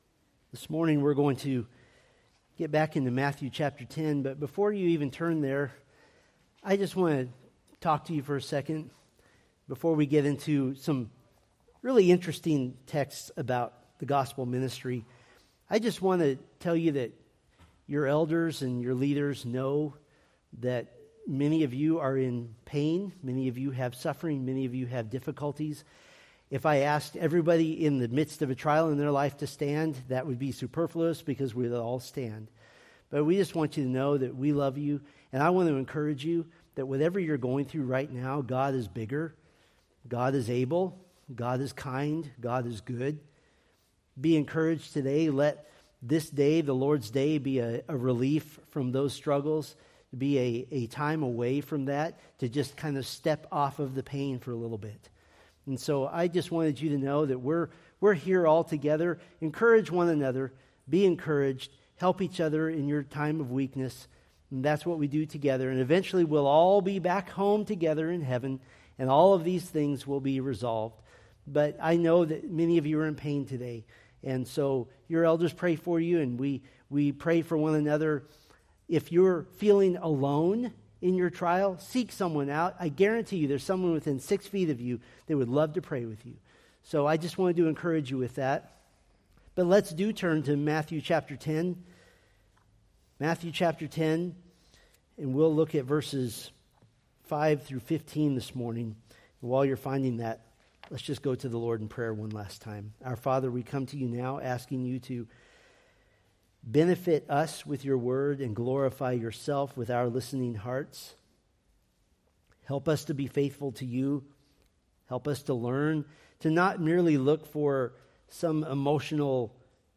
Preached April 27, 2025 from Matthew 10:5-15